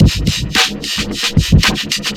ELECTRO 11-R.wav